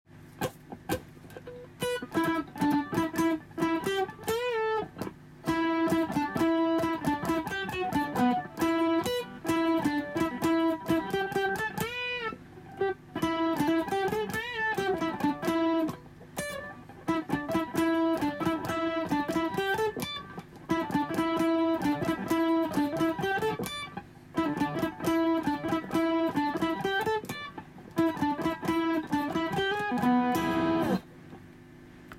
だいぶ余計な弦が鳴るタイミングは減らせてるかなー